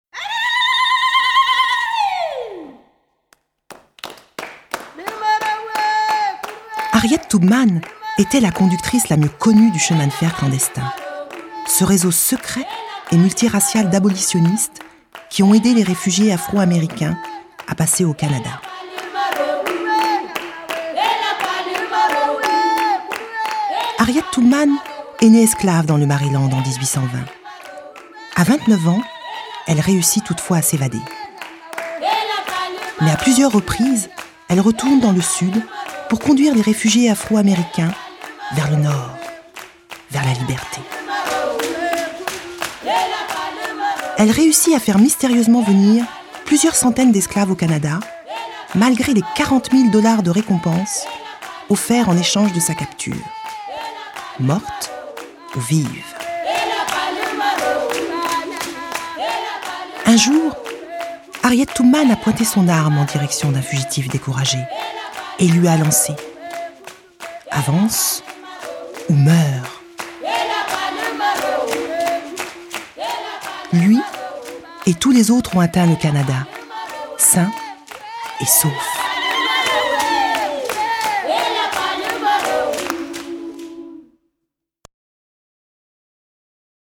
Narrateur